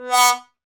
Index of /90_sSampleCDs/Roland L-CDX-03 Disk 2/BRS_Trombone/BRS_TromboneMute